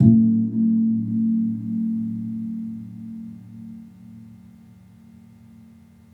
Gong-A1-f.wav